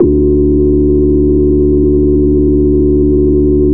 Overtone Bass 65-03.wav